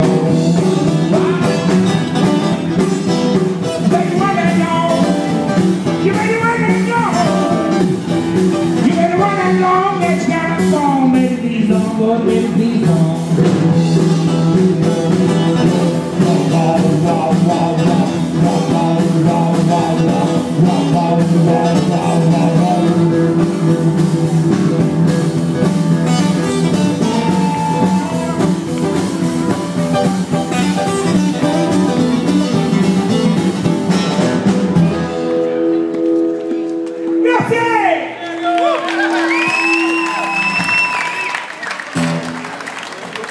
Live in Barga - first night - Piazza Angelio